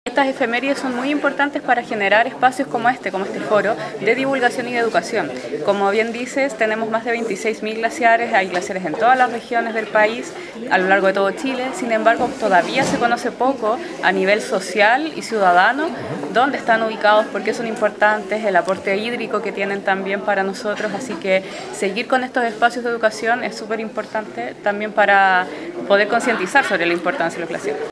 En el marco del Día Mundial del Agua, cada 22 de marzo, el Centro de Recursos Hídricos para la Agricultura y la Minería, Crhiam, organizó una nueva edición del Foro del Agua, con el título “Salvemos nuestros glaciares”.